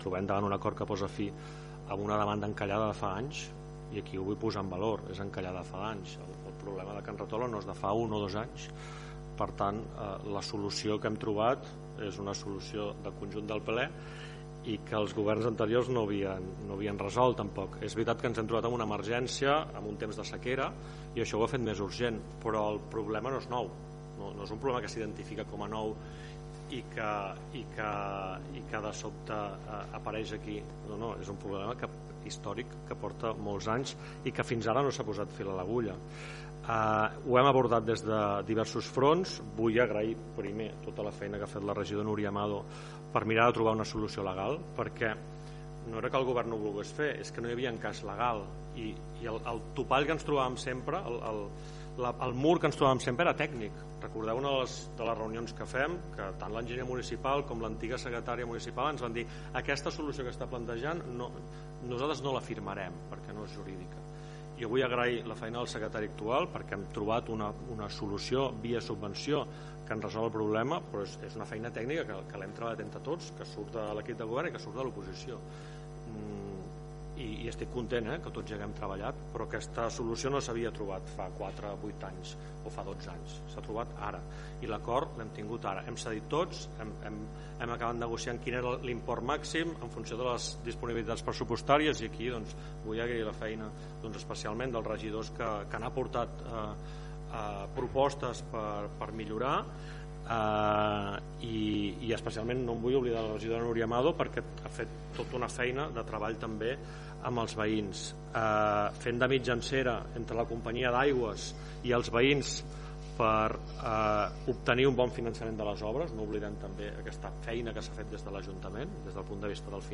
Segons va dir l’alcalde de Tiana, Isaac Salvatierra, aquest és un acord sorgit de la col·laboració entre govern i oposició “que posa solució a una demanda encallada de fa anys”: